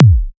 Urban Kick 02.wav